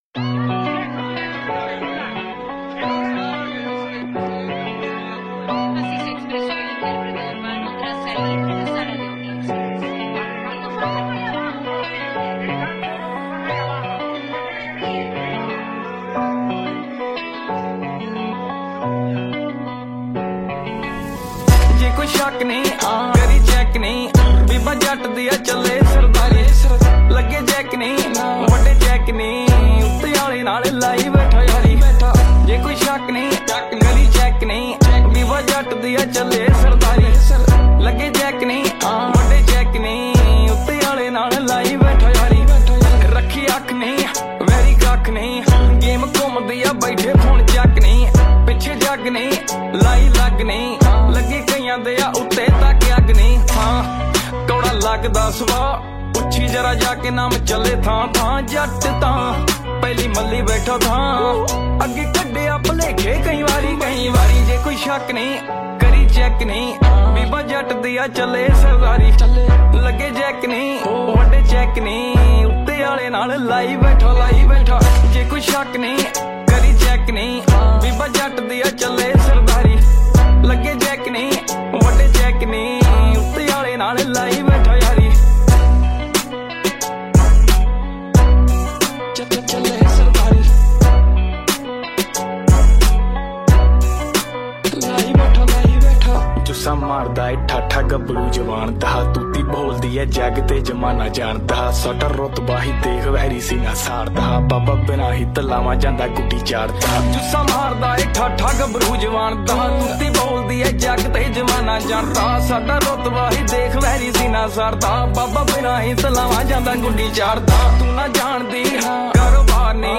Punjabi Mp3 Songs